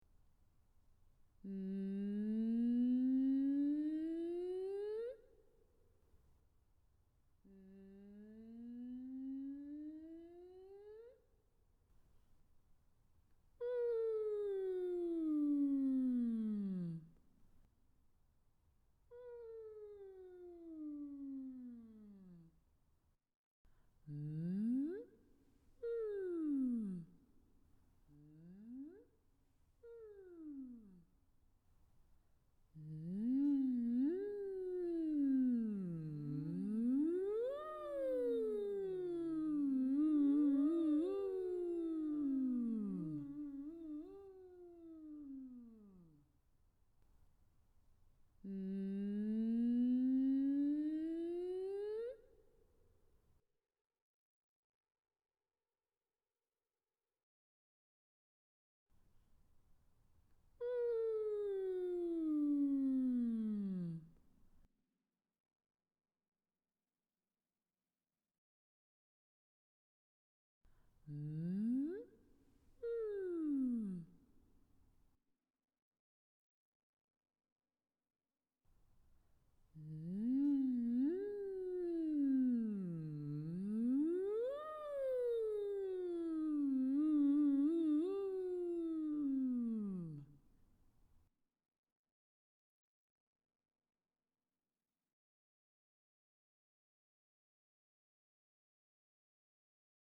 Stretching för rösten- glissando
Gör mjuka och följsamma glissandon uppåt och nedåt i slingor med början i mitten av ditt röstregister på olika språkljud.
mmmmmmmm
Mmmmm.mp3